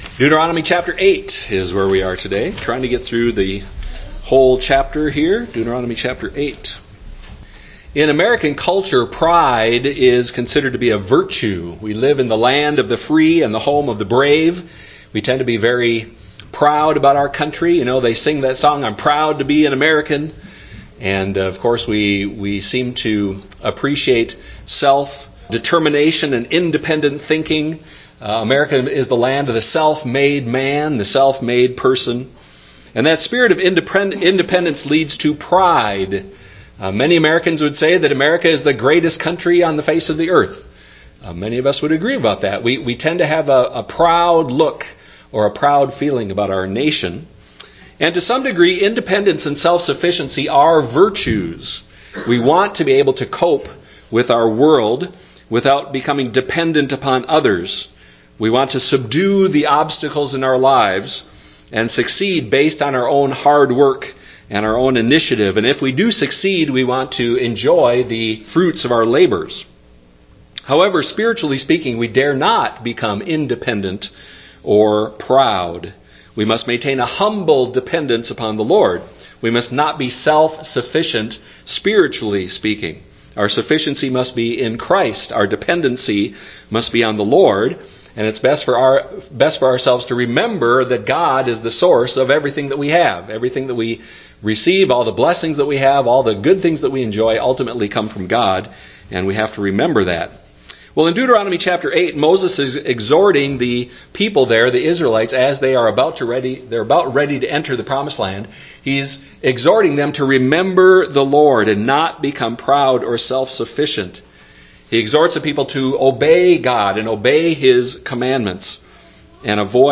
Note: The recording of this message is incomplete; the audio ends at about 23 minutes, roughly half way through the message–sorry, some kind of technical issue no doubt is to blame.
Service Type: Sunday morning worship service